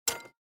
minislot_stop_1.mp3